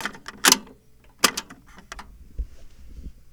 Index of /90_sSampleCDs/E-MU Producer Series Vol. 3 – Hollywood Sound Effects/Human & Animal/Cassette Door
CASSETTE 02R.wav